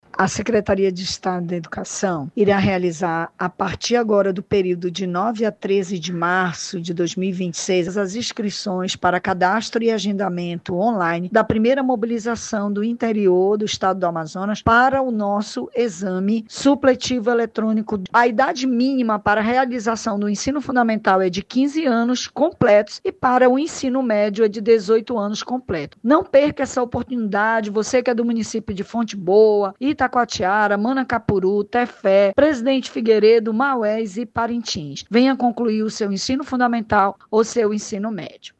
SONORA-PROVAO-ELETRONICO-.mp3